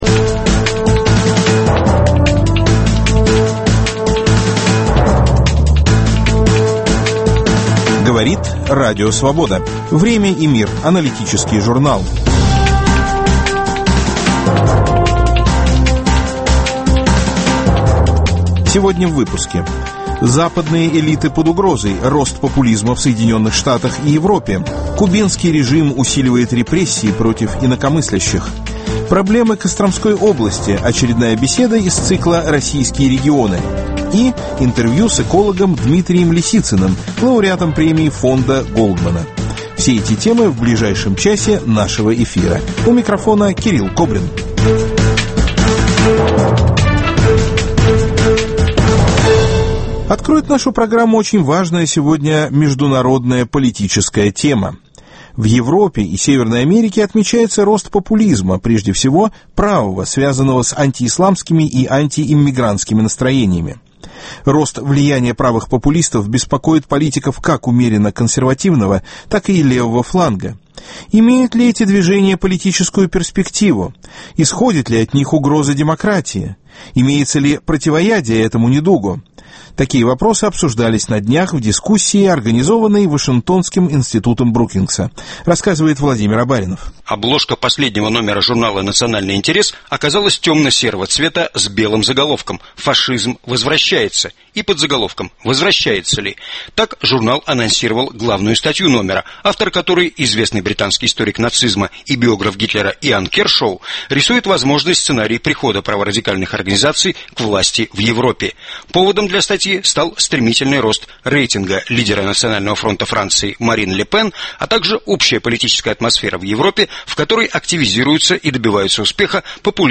Западные элиты под угрозой – рост популизма в Соединенных Штатах и Европе. Кубинский режим усиливает репрессии против инакомыслящих. Проблемы Костромской области – очередная беседа из цикла «Российские регионы». Интервью